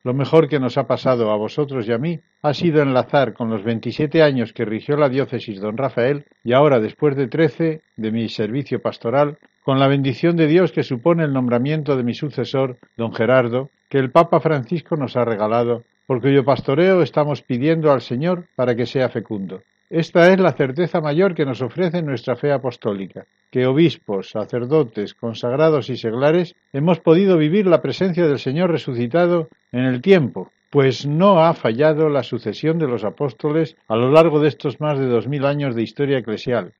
Antonio Algora, obispo emérito de Ciudad Real, en su despedida de la Diócesis